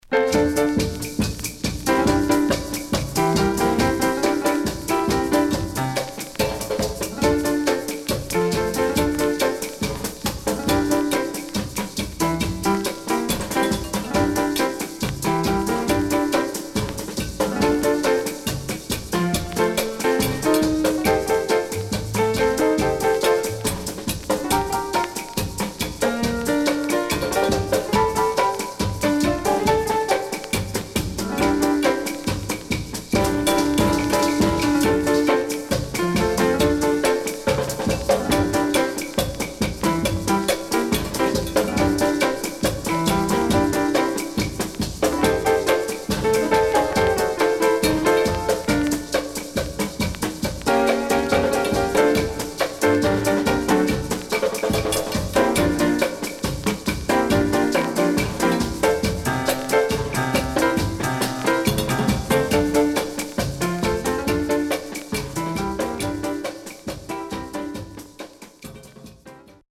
Rare.Jazzyかつ陽気なCarib Musicが堪能できる素晴らしいAlbum
SIDE B:全体的にノイズ入りますが音圧があるので聴けます。